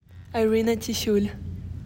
Pronunciation: eye REE nuh tih SHOOL